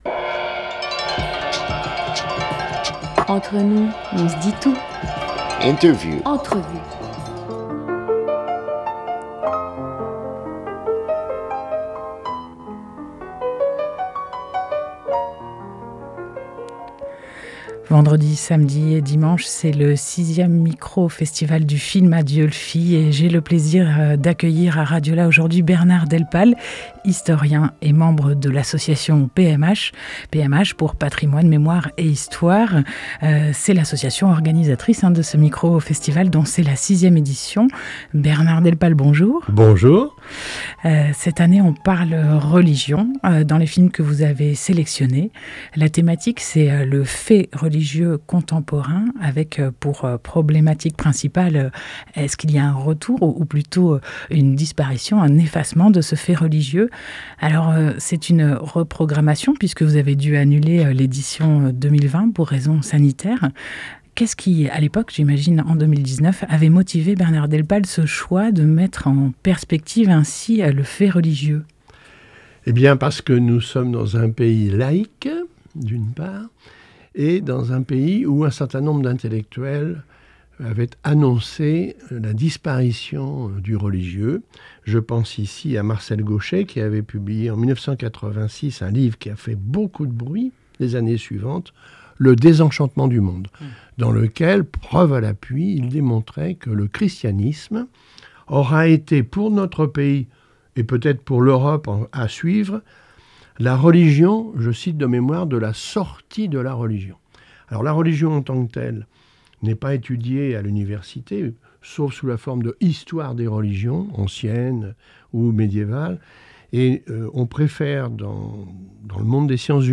9 novembre 2021 14:31 | Interview